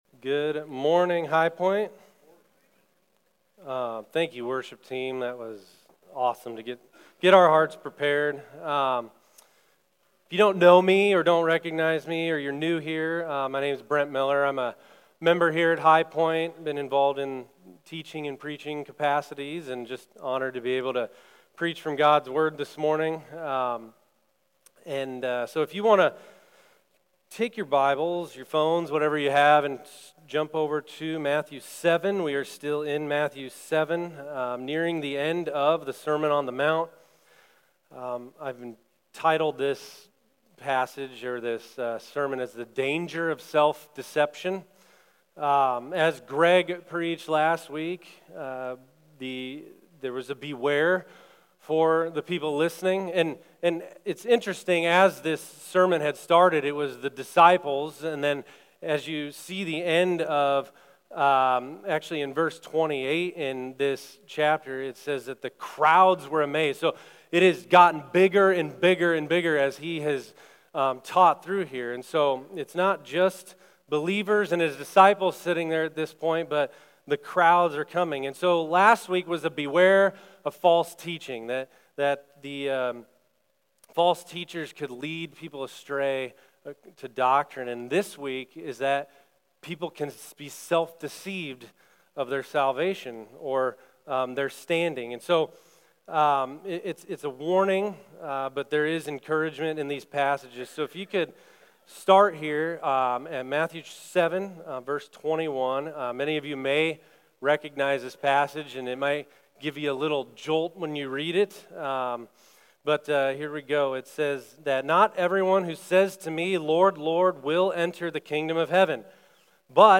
Sermon Questions Read Matthew 7:21-23.